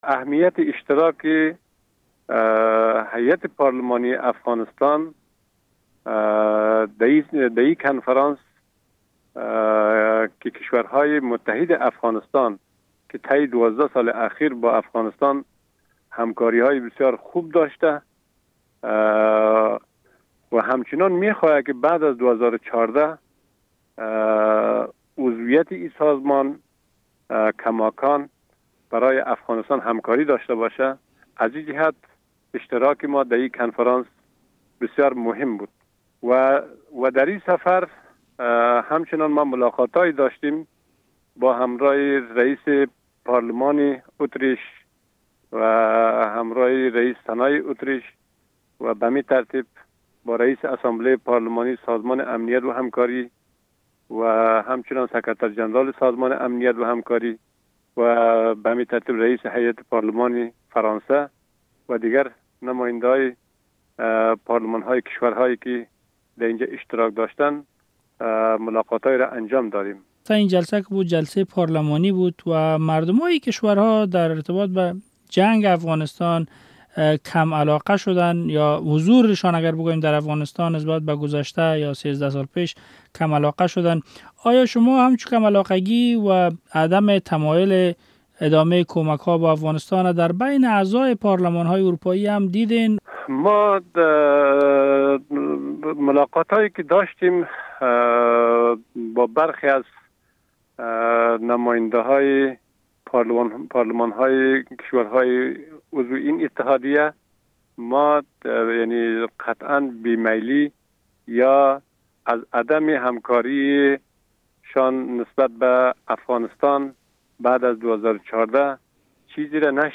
مصاحبه با عبدالرووف ابراهیمی رییس ولسی جرگهء افغانستان